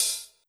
TEC Open Hat.wav